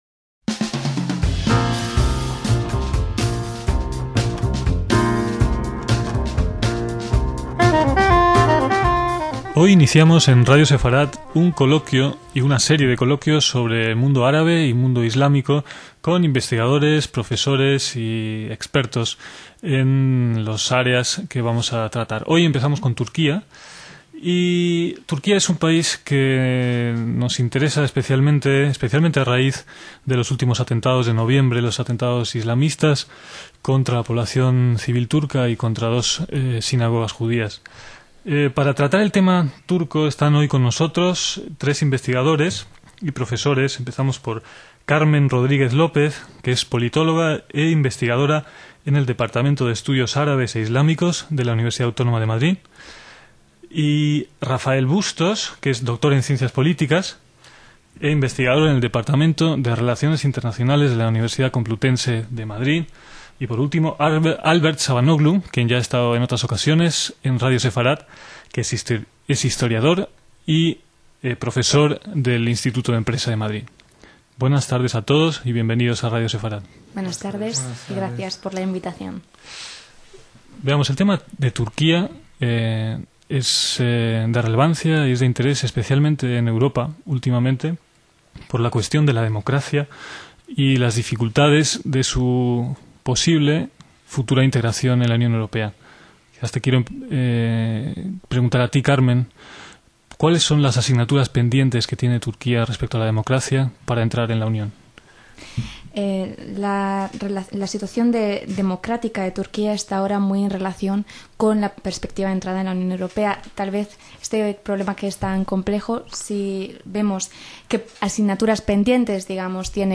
DECÍAMOS AYER - En esta sección recuperamos debates, mesas redondas y reportajes con varios participantes en discusiones sobre temas que fueron de actualidad incluso hace 20 años y que, en algunos casos, conservan toda o gran parte de su vigencia.